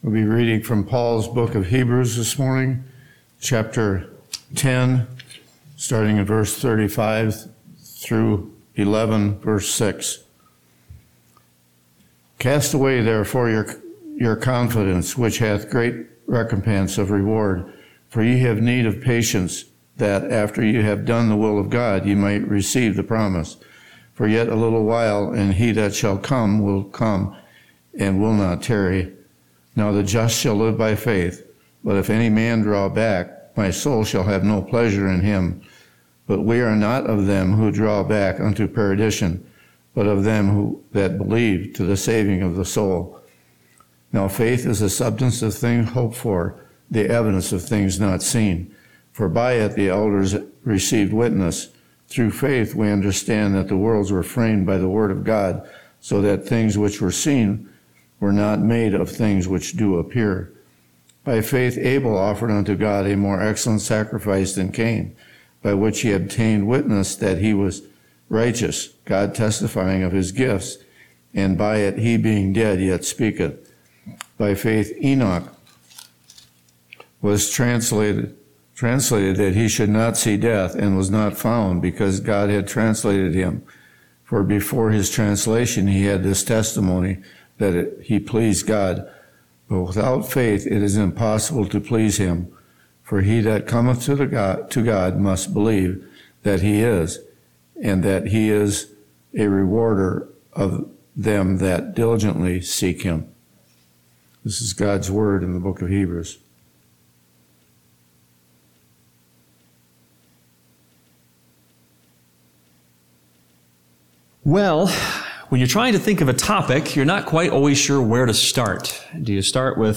Though eternal security assures us of our salvation, the Scriptures call us to press on, challenging us with promises of future rewards for those who persevere. This sermon will clear the confusion, ignite your spirit to run with endurance, and empower you to press forward, knowing that the prize awaits those who faithfully endure.